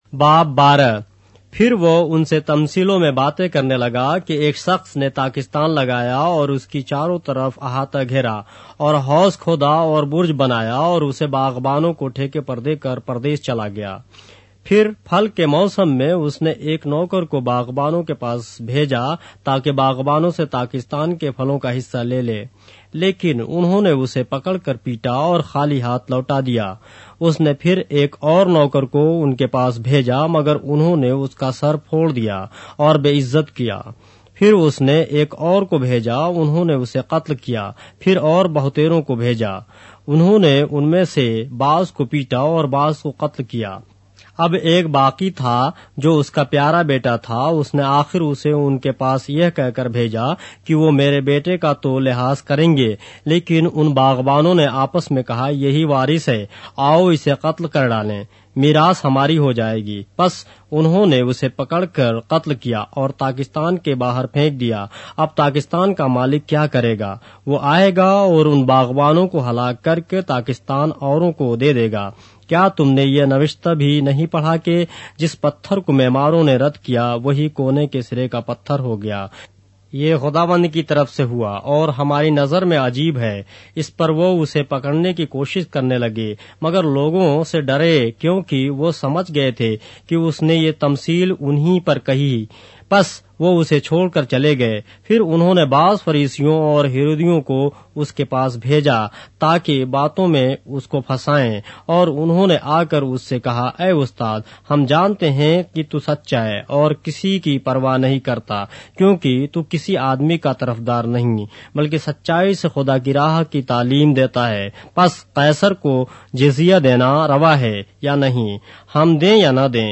اردو بائبل کے باب - آڈیو روایت کے ساتھ - Mark, chapter 12 of the Holy Bible in Urdu